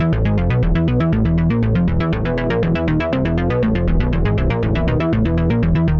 Index of /musicradar/dystopian-drone-samples/Droney Arps/120bpm
DD_DroneyArp2_120-E.wav